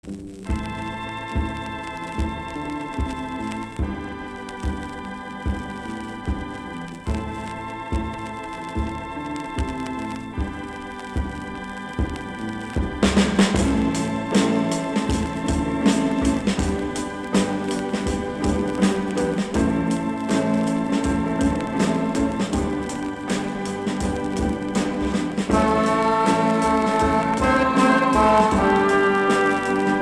Progressif Unique 45t